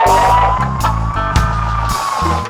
DUBLOOP 01-L.wav